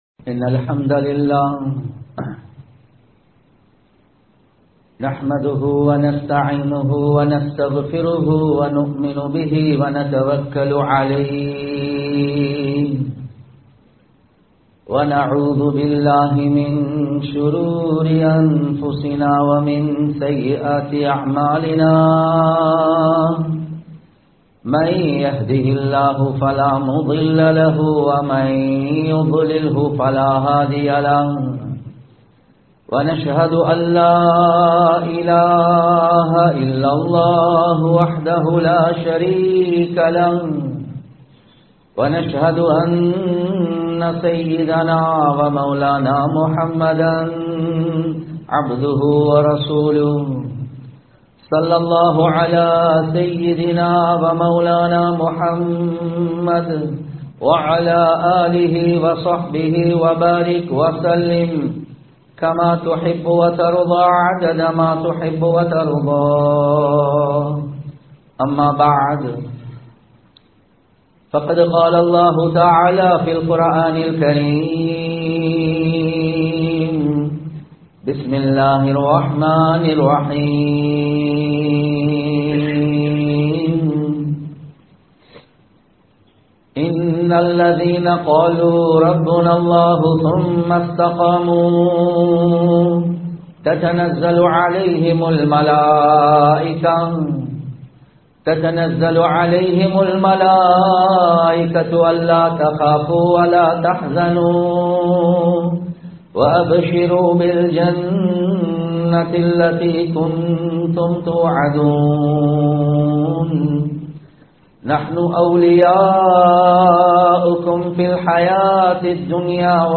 வீட்டில் நற்பண்புகளுடன் வாழ்வோம் | Audio Bayans | All Ceylon Muslim Youth Community | Addalaichenai
Thaqwa Jumua Masjith